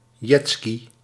Ääntäminen
Ääntäminen Tuntematon aksentti: IPA: /dʒɛtˈski/ Haettu sana löytyi näillä lähdekielillä: hollanti Kieli Käännökset englanti jetski ruotsi vattenskoter Suku: m .